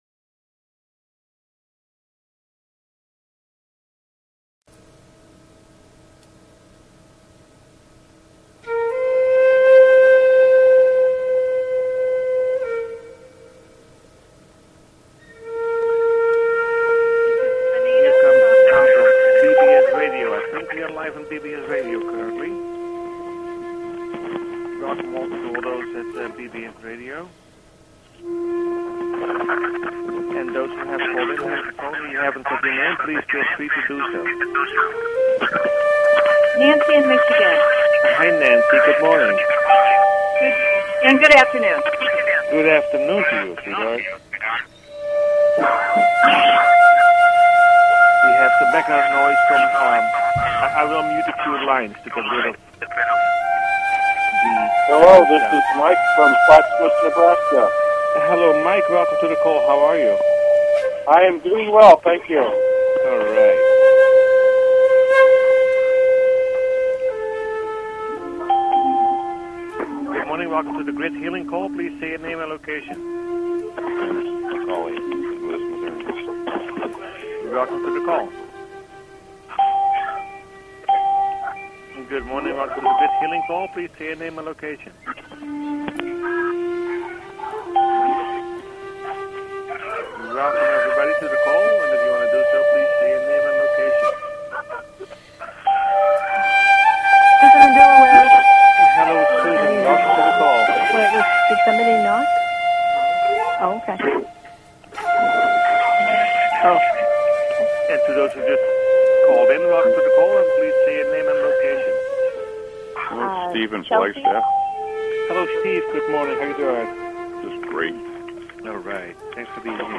Talk Show Episode, Audio Podcast, Personal_Planetary_Healing_Meditation and Courtesy of BBS Radio on , show guests , about , categorized as